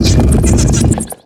Cri de Crabaraque dans Pokémon X et Y.